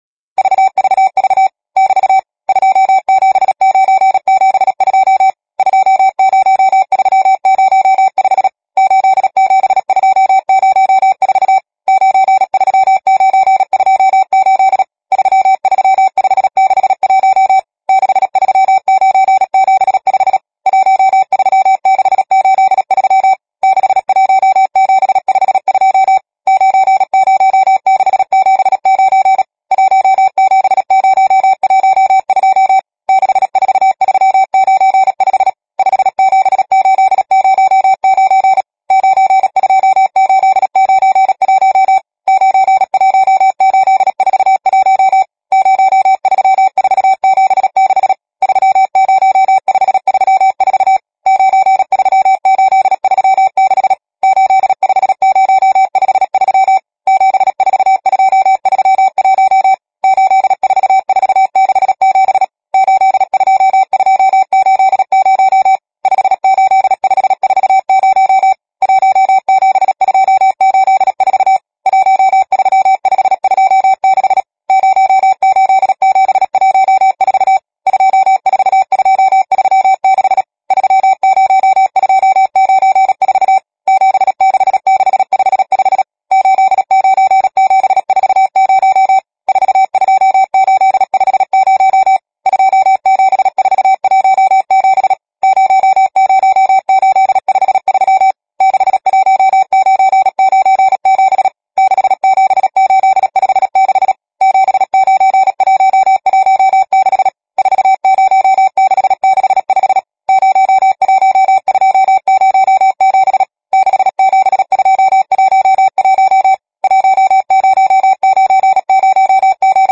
Oba texty jsou nahrané stejným tempem 100 RM ve tvaru „VVV = soutěžní text +“. Soutěžní text je sestaven z padesáti pětimístných skupin podle pravidel radioamatérského víceboje, disciplíny příjem Morseovy abecedy.